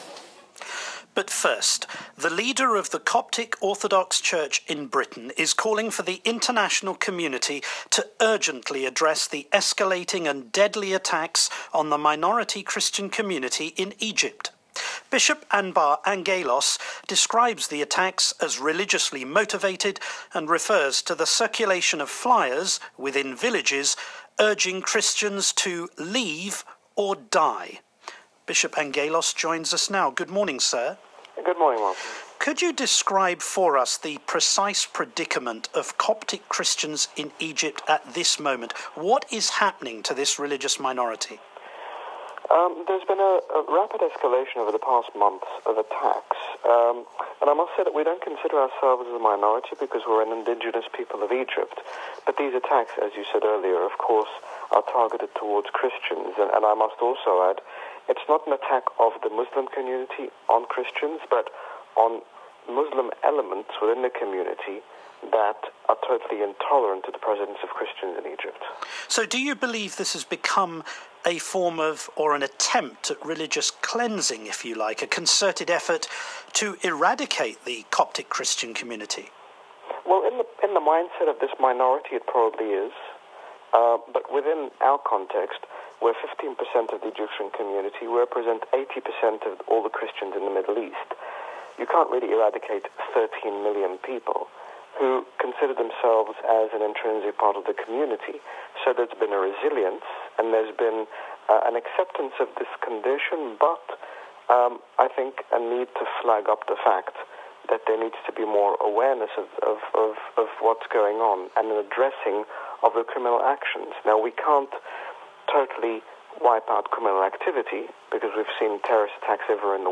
Martin Bashir interviews His Grace Bishop Angaelos, General Bishop of the Coptic Orthodox Church in the United Kingdom, on BBC Radio 4's Sunday Programme, on the attacks against Coptic Christians in Egypt and the upcoming visit of His Holiness Pope Francis of Rome to Egypt.